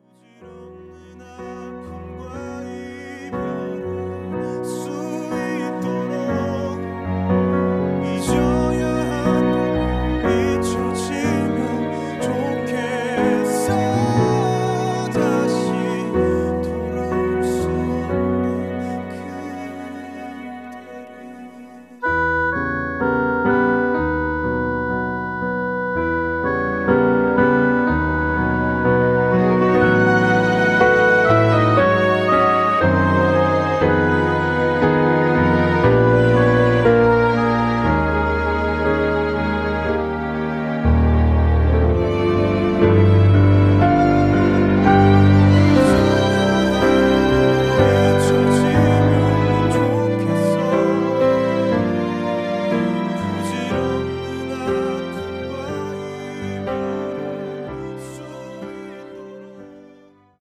음정 -1키 3:10
장르 가요 구분 Voice Cut